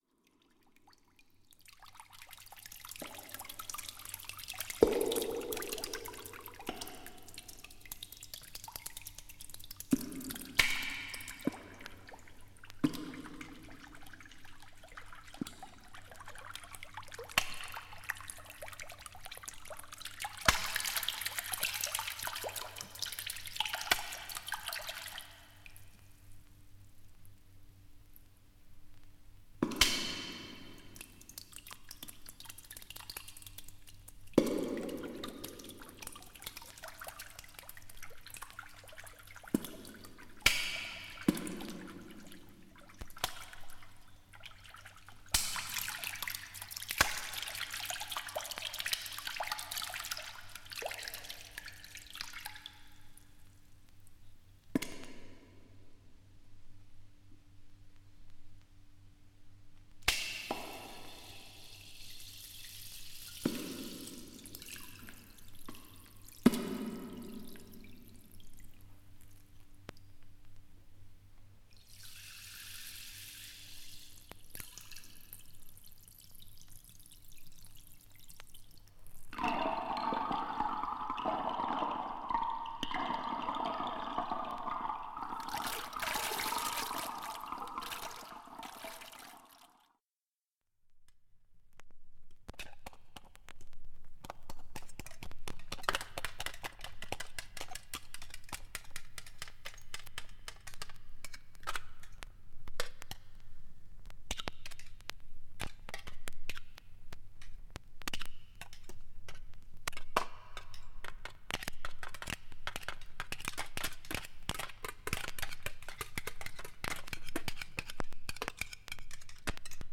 水が出す色々な音を収録した2枚組（笑）